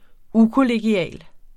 Udtale [ ˈu- ]